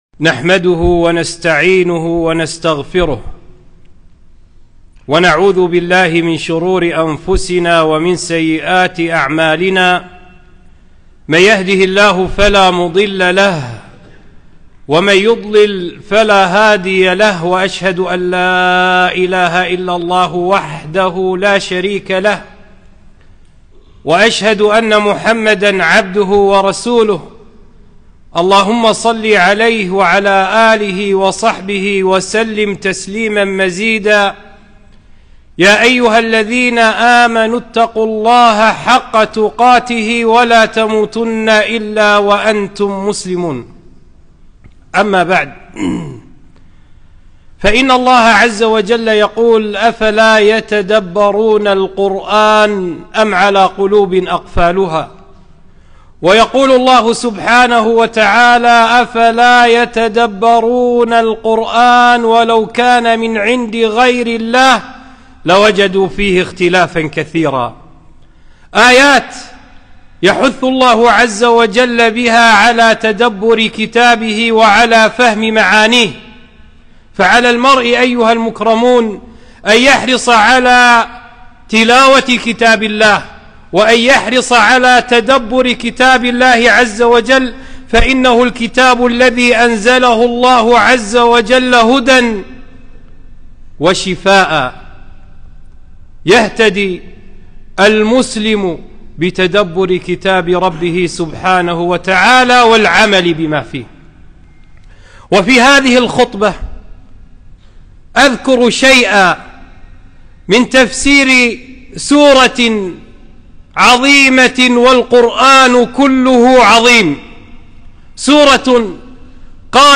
خطبة - بعض معاني سورة (العصر)